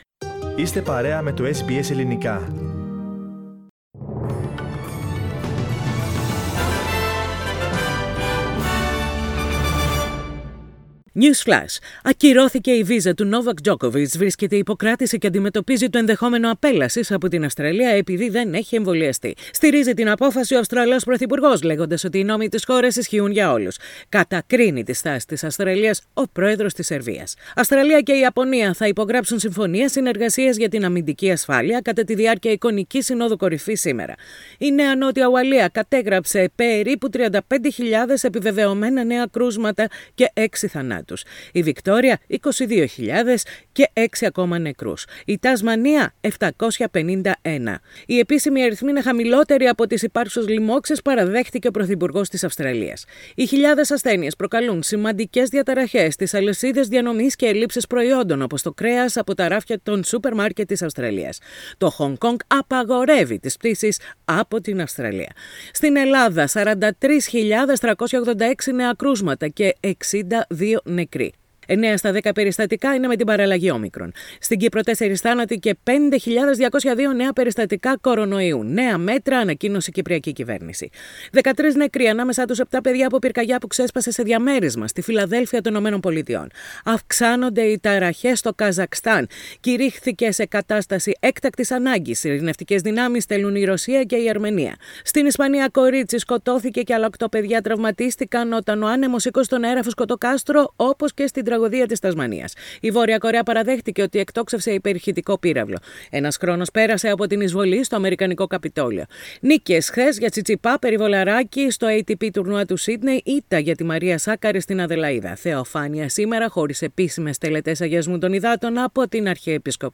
The main news headlines of the day, in 2 minutes.